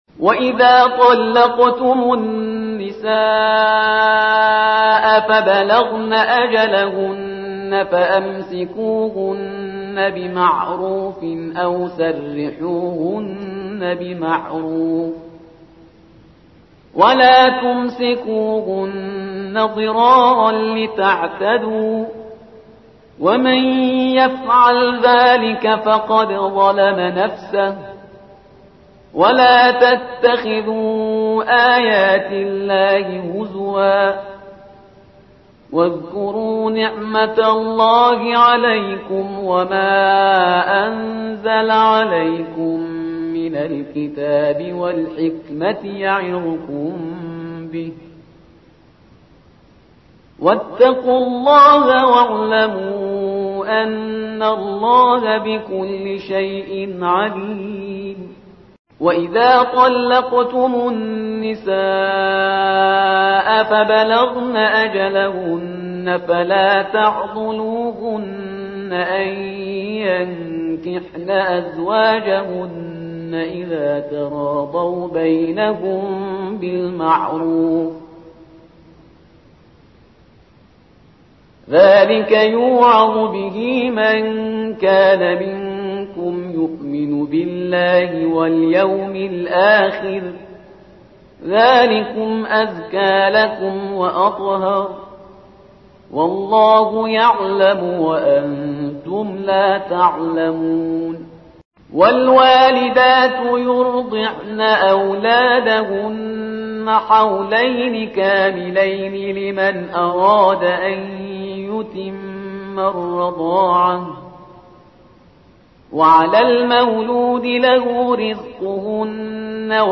ترتیل صفحه ۳۷ سوره مبارکه بقره با قرائت استاد پرهیزگار(جزء دوم)